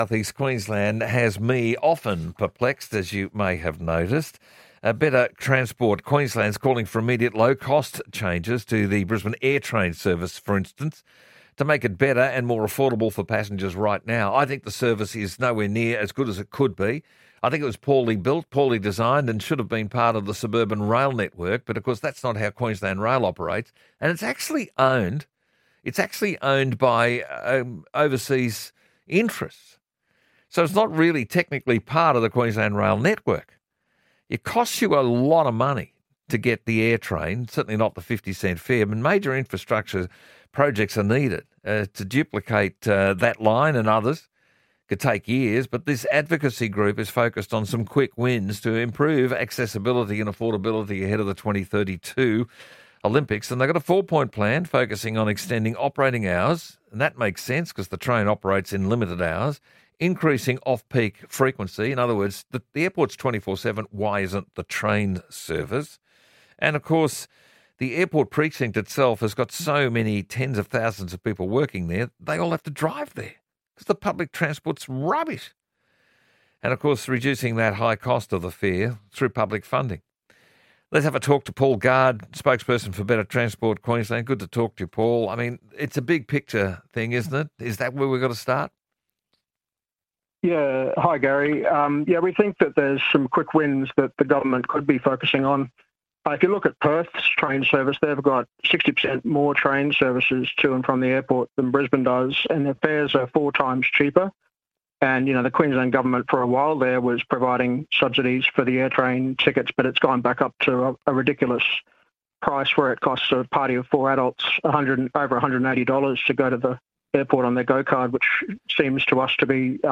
Interview 4BC Drive 24th Oct 2025